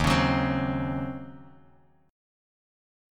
D#m13 Chord
Listen to D#m13 strummed